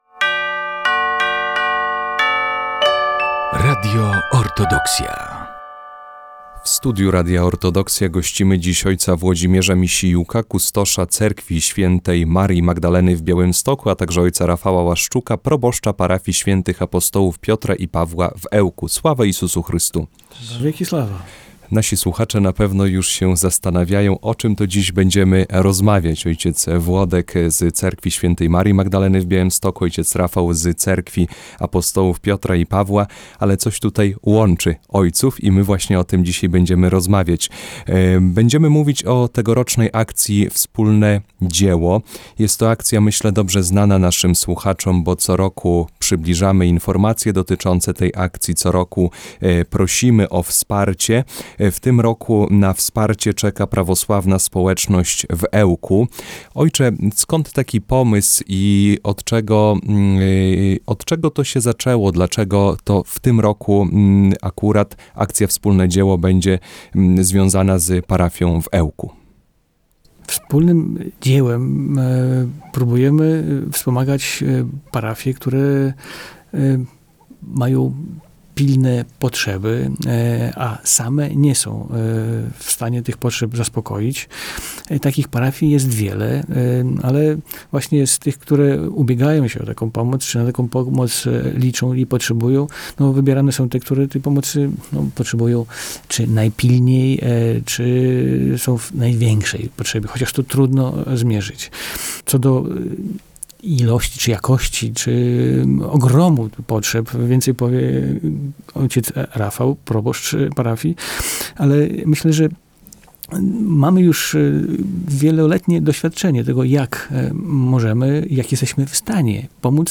Akcja Wspólne Dzieło 2025. Rozmowa